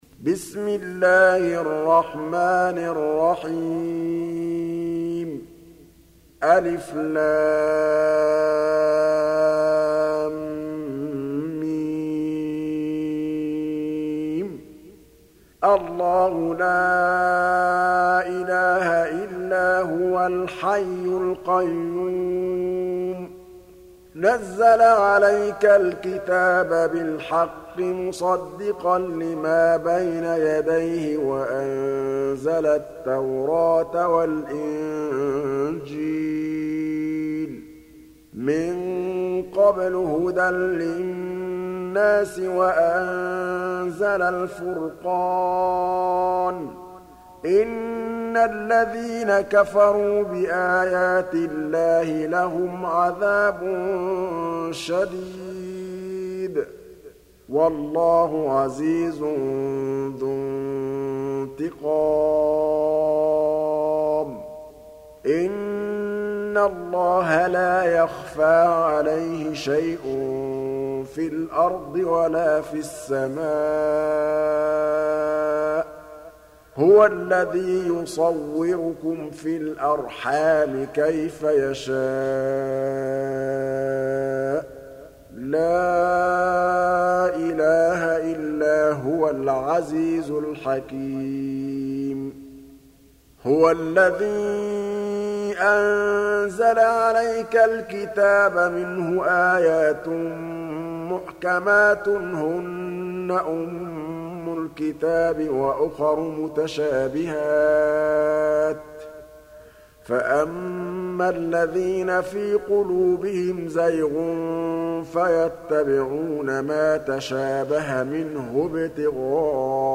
سورة آل عمران Audio Quran Tarteel Recitation
حفص عن عاصم Hafs for Assem